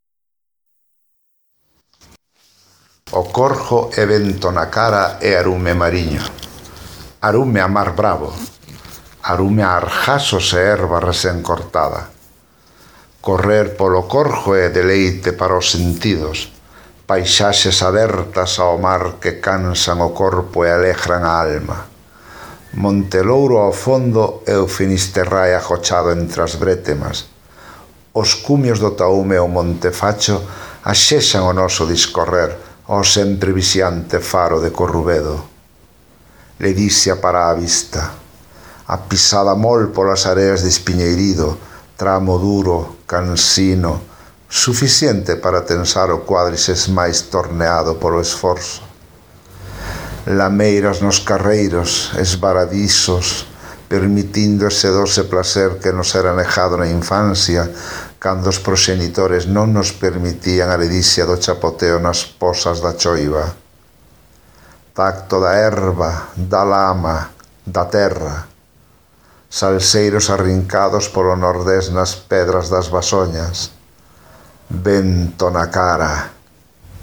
Escoita o seguinte texto da Festa da Dorna de Ribeira, poñendo atención ás características propias desta área.